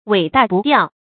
wěi dà bù diào
尾大不掉发音
成语注音 ㄨㄟˇ ㄉㄚˋ ㄅㄨˋ ㄉㄧㄠˋ